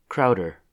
Steven Blake Crowder (/ˈkrdər/
En-us-Crowder.oga.mp3